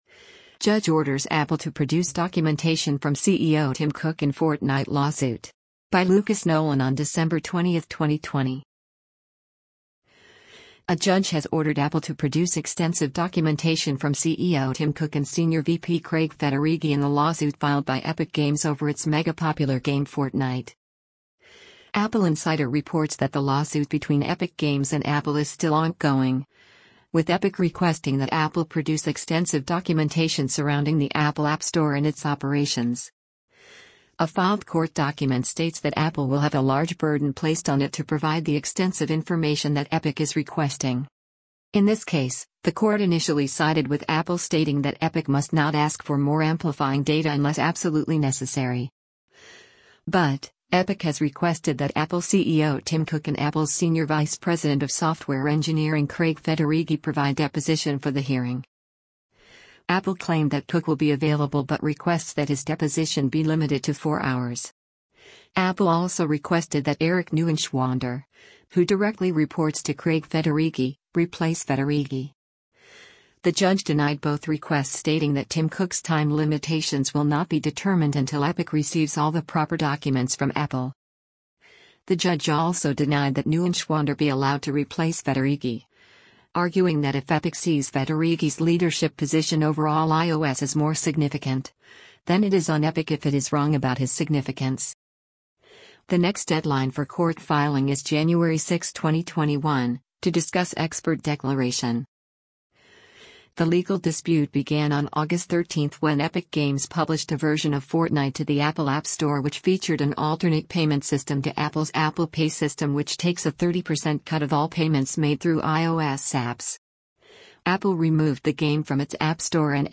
Tim Cook CEO of Apple testifies to Congress